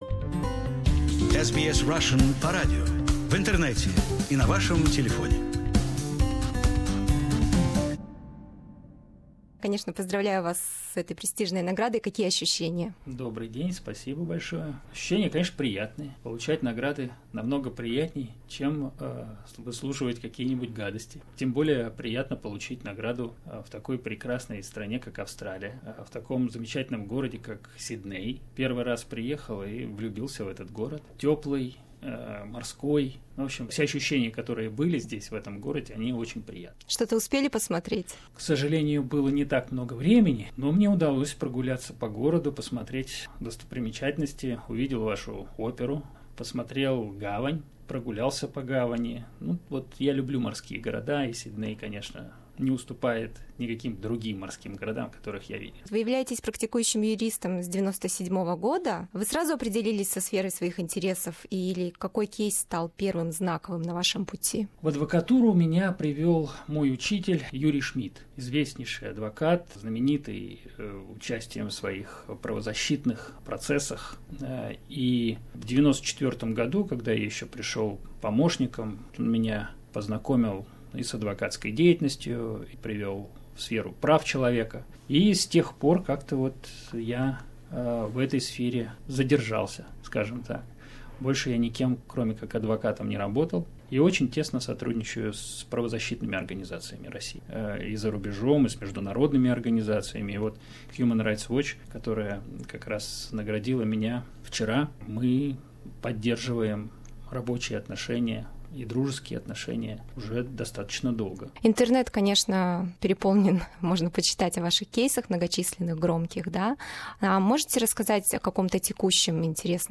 В интервью нашей программе он рассказал о своей борьбе за верховенство закона и освобождение невинных жертв, отбывающих незаконное наказание, о промывании мозгов российской пропагандистской машиной и отсутствии альтернативных источников информации для рядовых россиян.